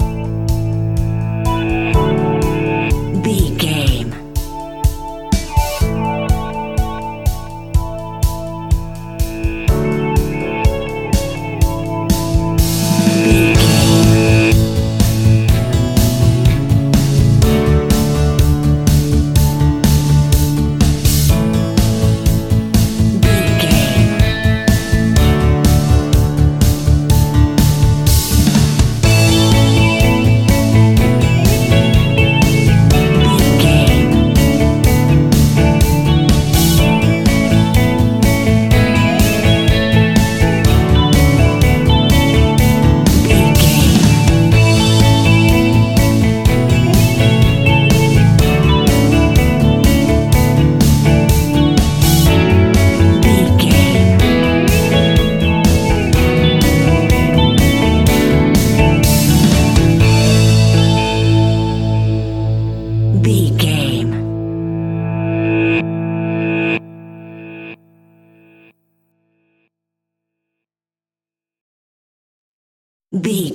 Ionian/Major
pop rock
indie pop
fun
energetic
uplifting
cheesy
instrumentals
guitars
bass
drums
piano
organ